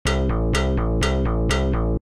flagtaken_opponent.ogg